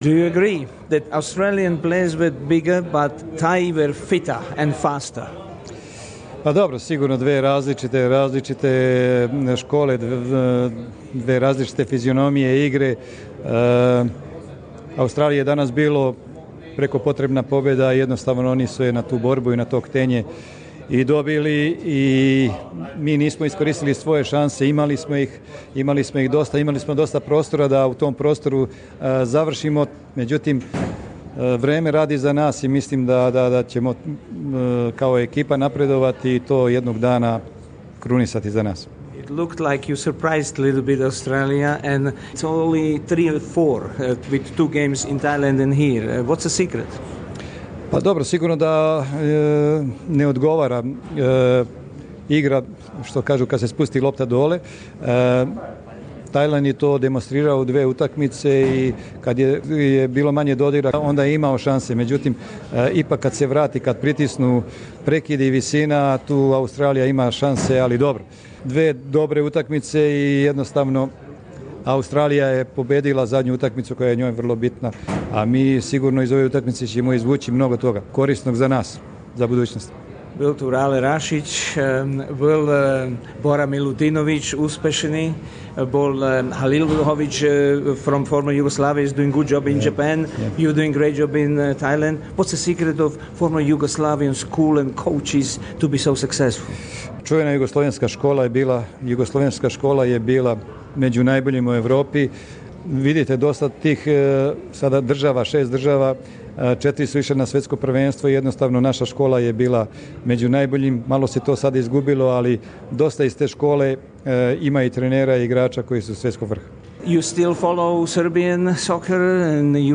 У екслузивном интервјуу за СБС селектор фудбалске репрезентације Тајланда Милован Рајевац говорио о утакмици са Аустралијом и великим очекивањима од српског државног тима до краја мундијалских квалификација...